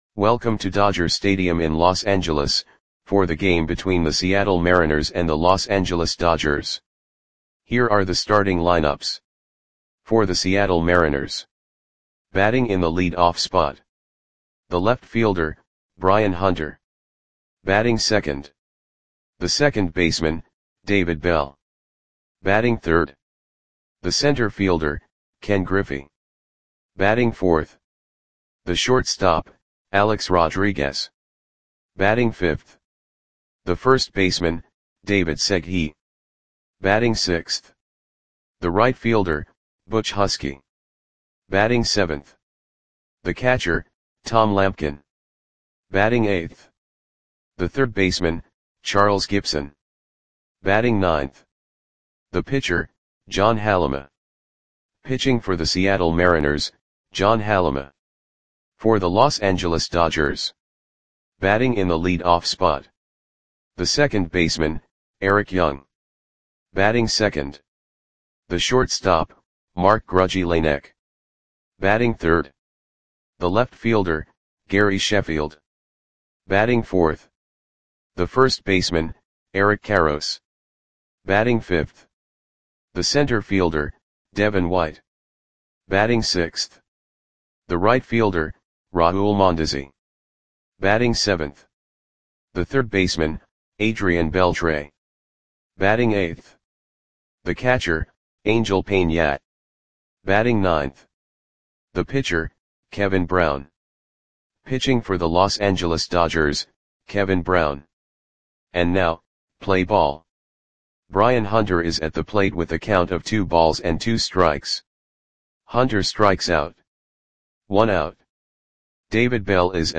Audio Play-by-Play for Los Angeles Dodgers on July 10, 1999
Click the button below to listen to the audio play-by-play.